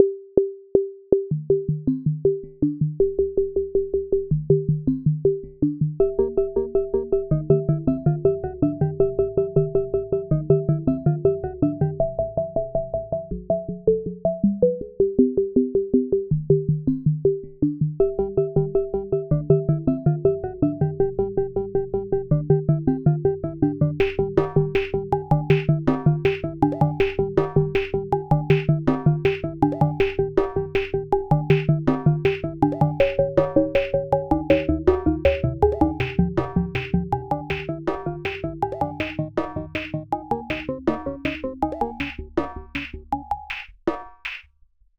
Música electrónica
rapsodia
romanticismo
sintetizador